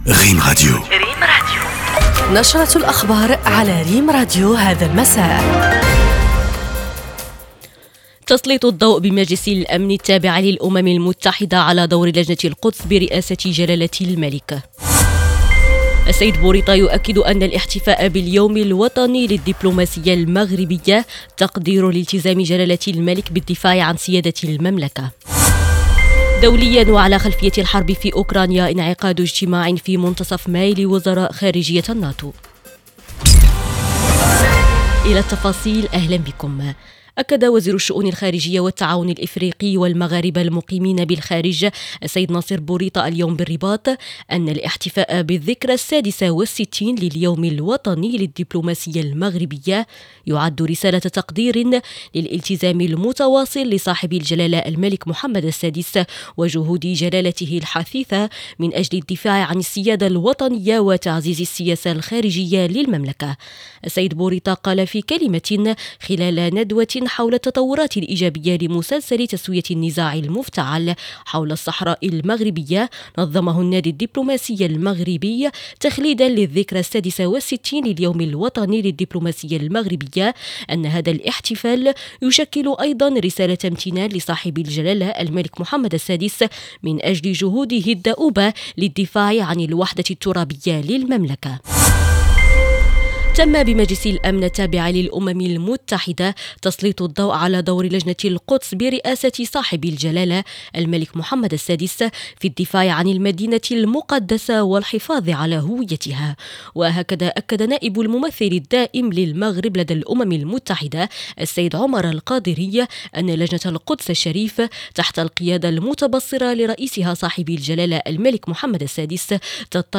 النشرة المسائية